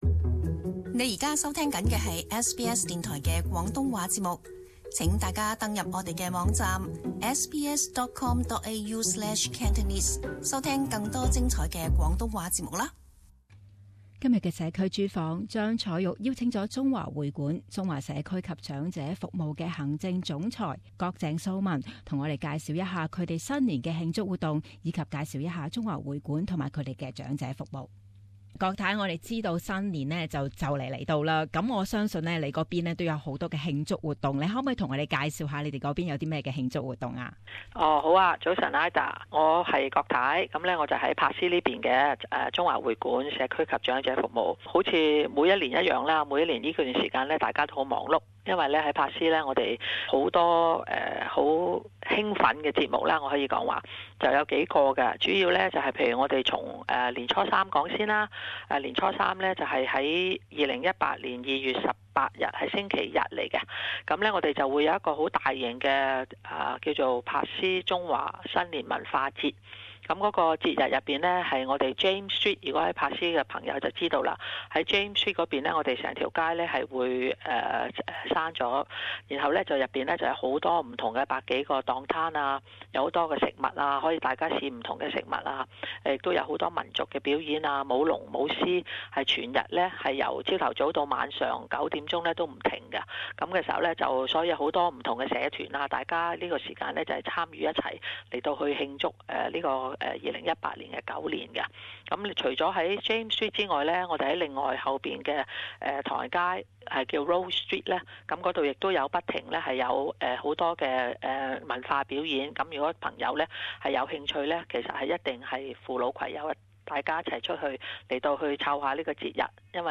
【社區專訪】中華社區及長者服務